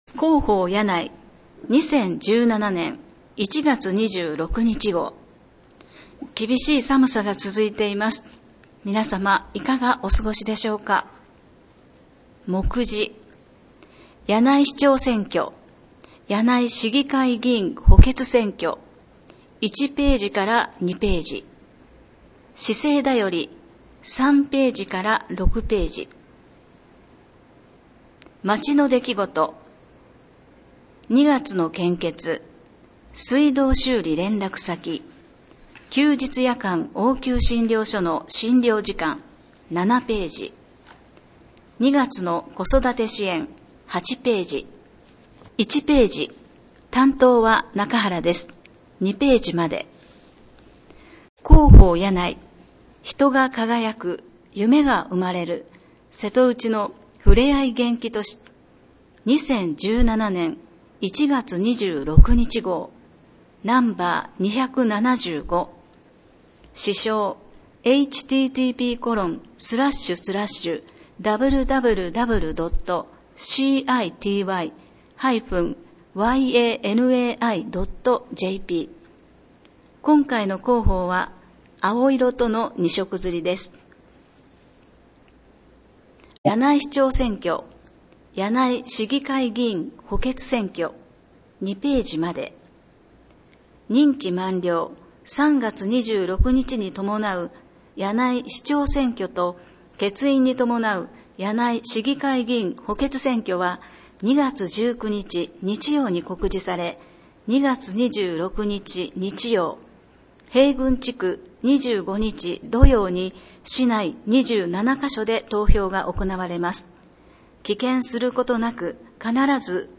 「音訳しらかべの会」の皆さんによる声の広報（音訳版広報）を、発行後1週間程度で掲載しています。